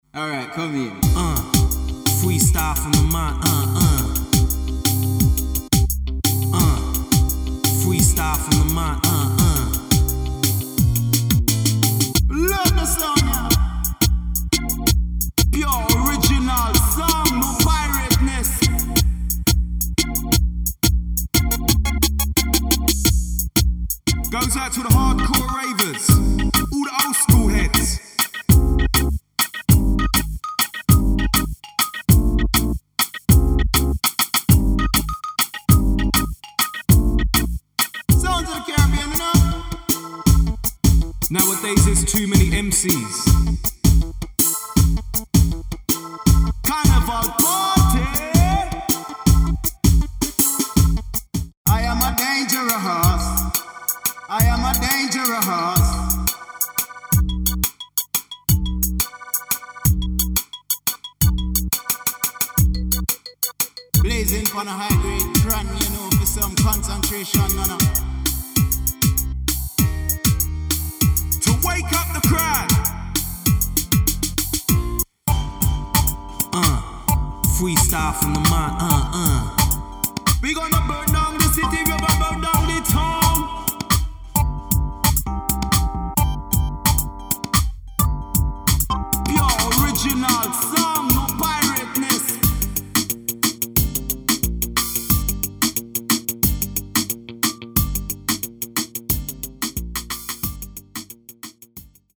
Tempo range: 80 to 132 BPM